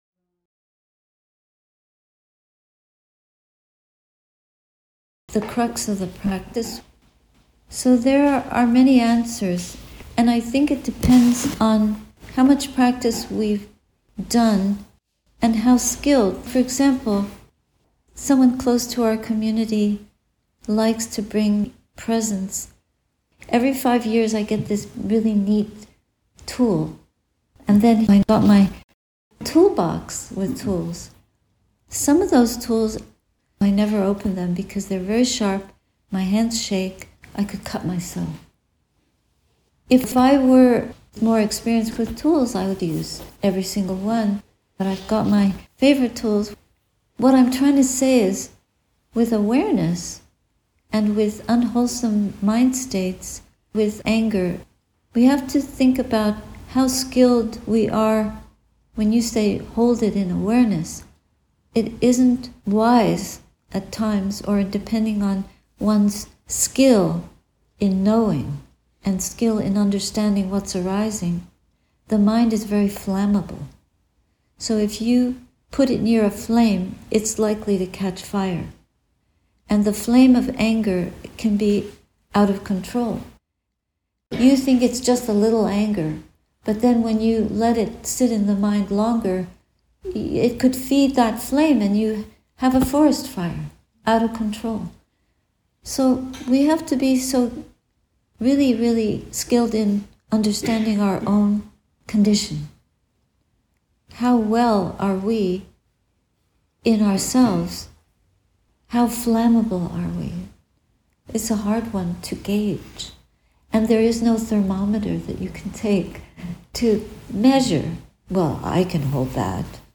Ottawa Buddhist Society, Quaker House, June 21, 2025